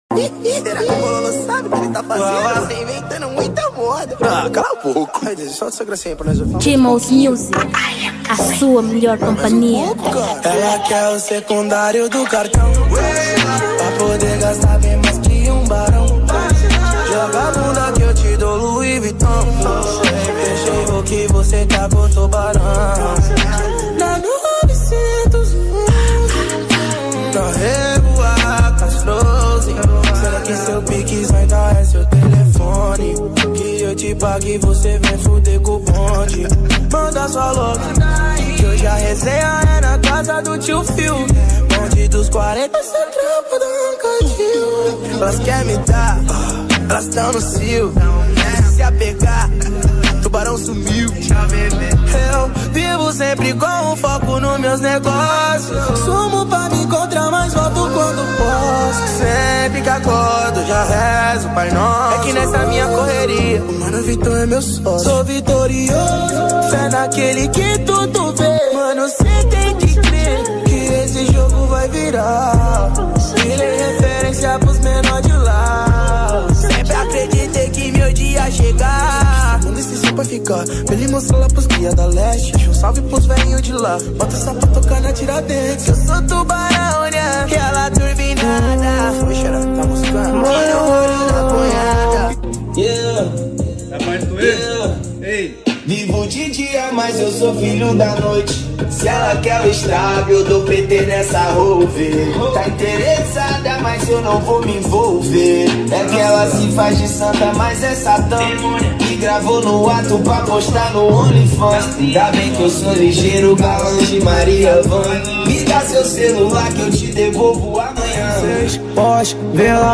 2023 Gênero: Trap Tamanho